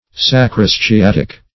Search Result for " sacrosciatic" : The Collaborative International Dictionary of English v.0.48: Sacrosciatic \Sa`cro*sci*at"ic\, a. (Anat.)